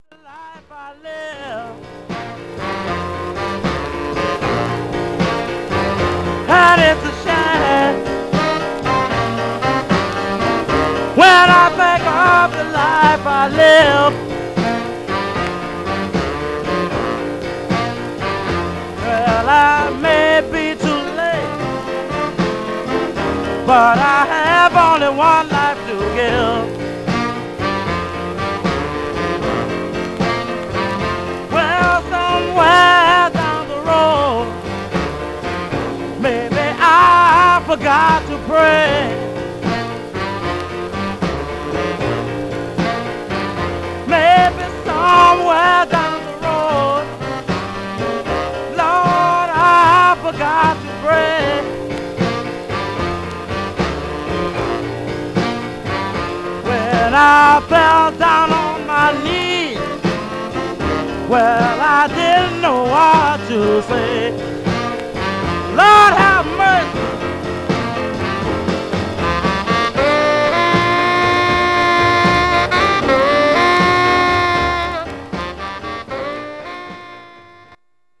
Some surface noise/wear
Mono
Rythm and Blues Condition